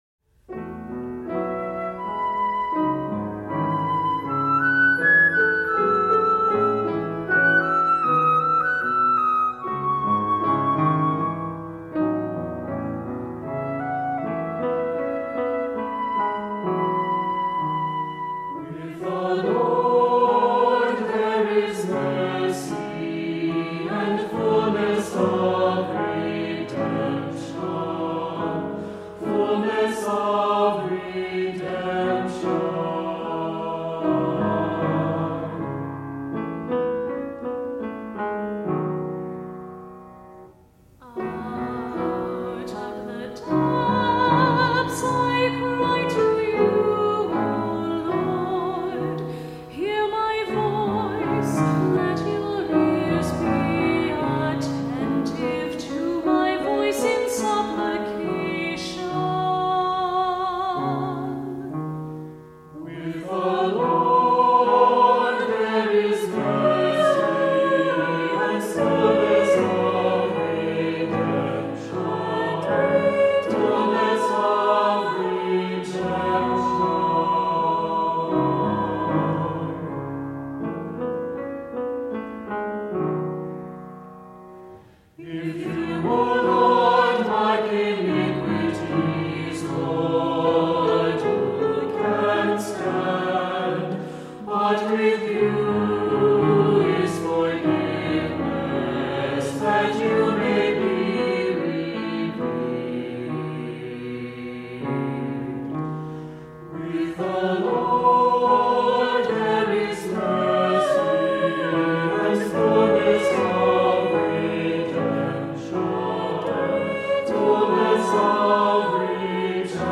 Voicing: Unison; Descant; Cantor; Assembly